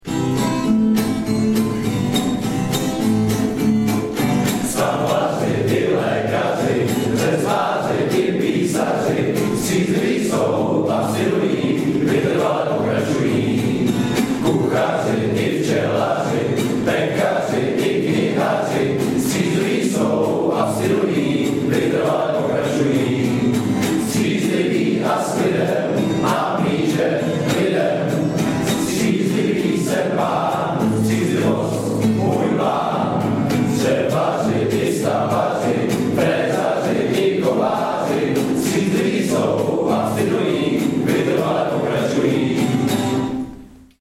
Vytrvale pokračují: stejný text, ale jiné hudební pojetí než minule.